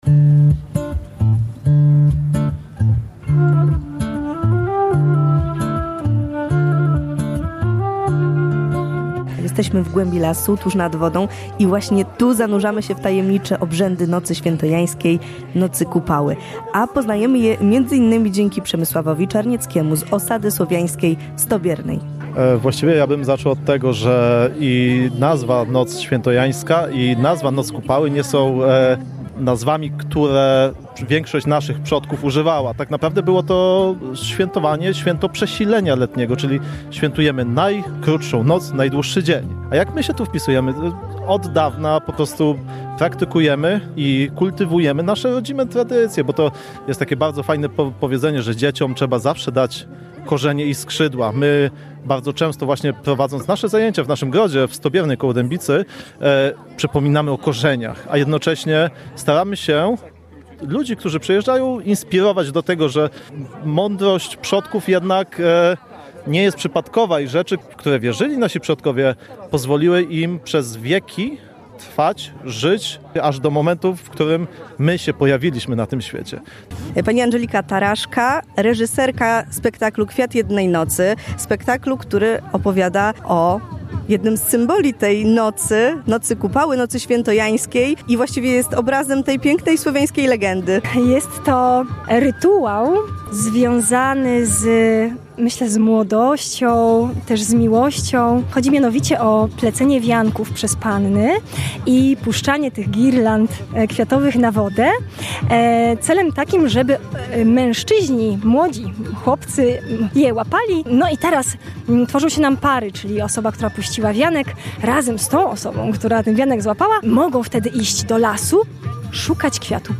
Tuż przed zmrokiem w Leśnictwie Sokole (powiat mielecki) setki mieszkańców przybyły na obchody Nocy Kupały.